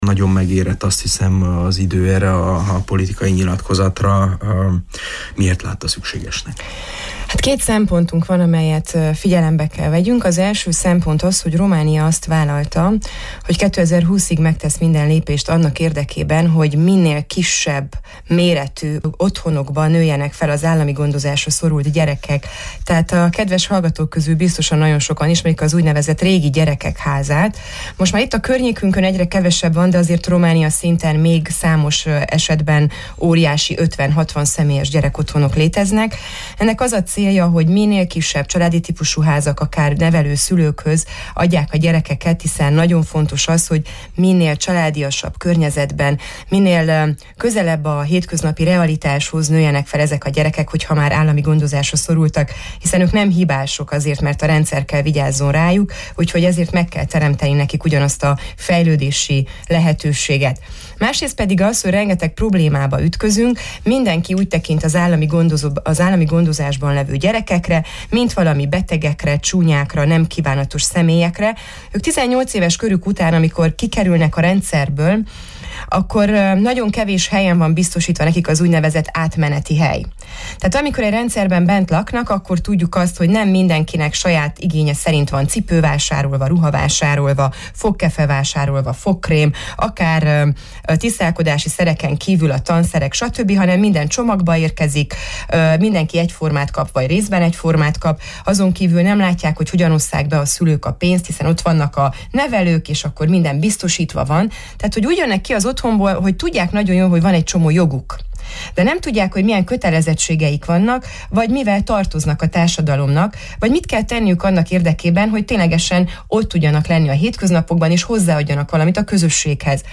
A jelenlegi helyzetről és kilátásokról számolt be Csép Éva Andrea Maros megyei parlamenti képviselő, a Képviselőház munkaügyi és szociális bizottság titkára ma délelőtti Miben segíthetünk műsorunkban, ebből hallhatnak részletet.